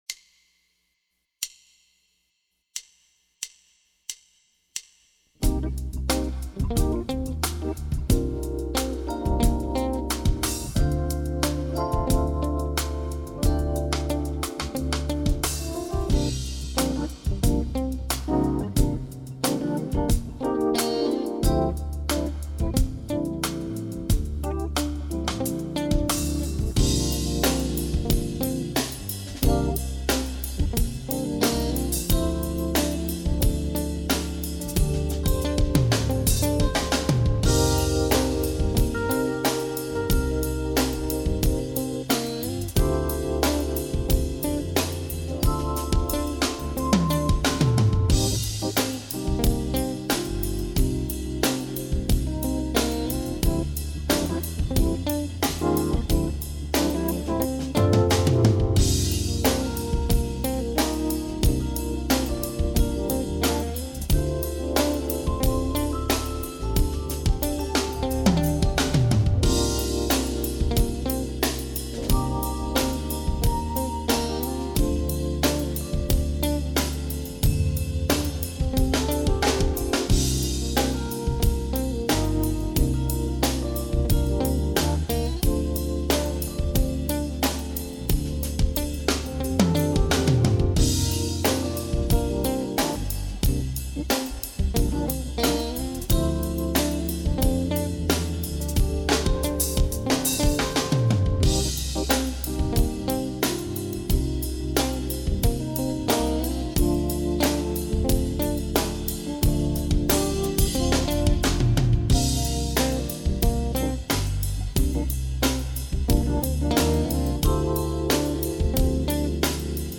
In a sentence: Cool, funky and relaxed.
Backing Track Dorian 1: